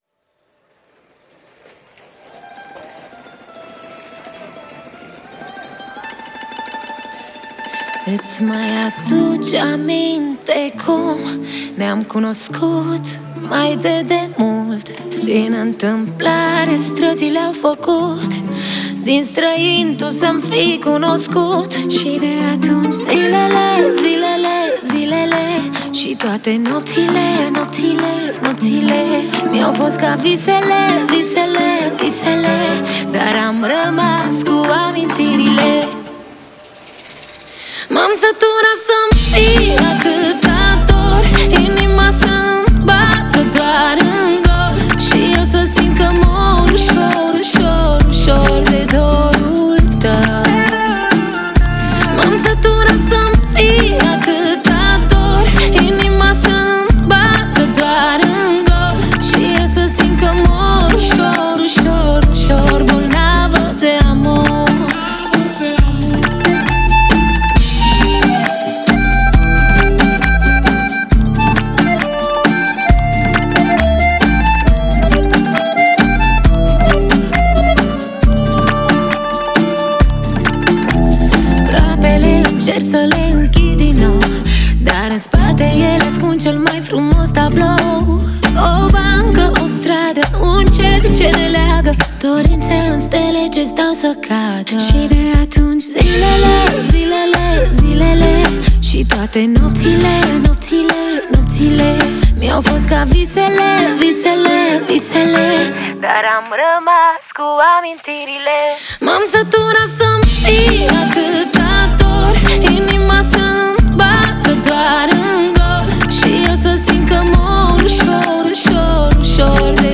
это зажигательная поп-песня